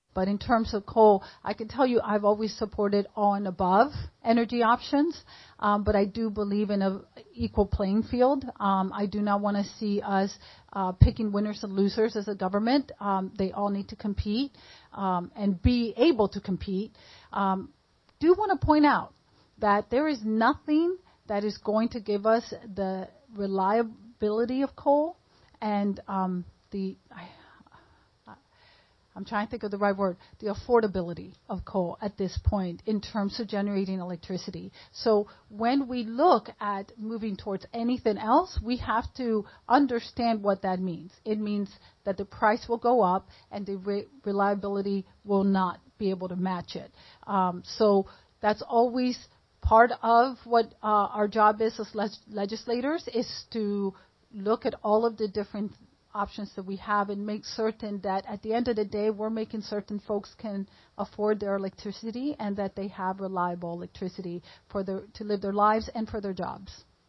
Jefferson Co. Senate Candidates Debate Education, Environment, Abortion - West Virginia Public Broadcasting